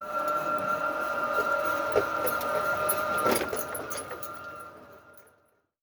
Golf Cart Normal.wav